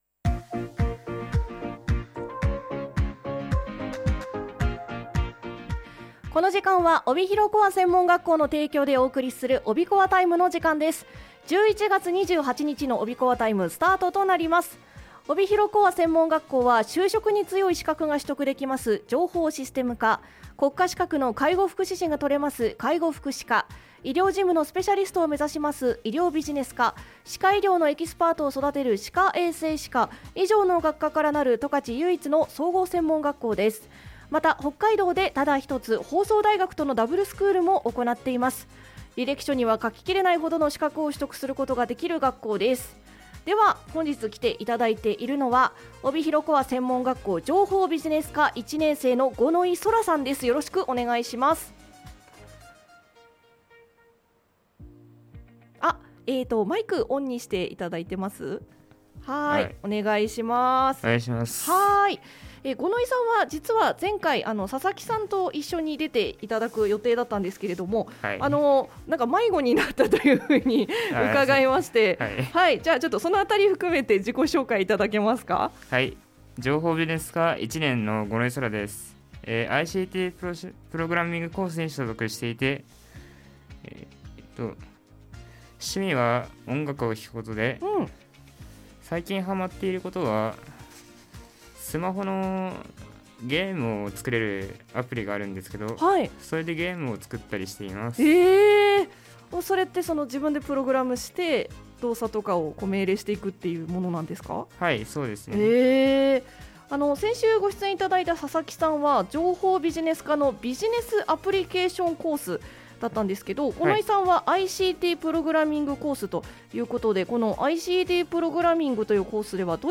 前回出演出来なかった分、 一人で一生懸命おしゃべりしてくれました！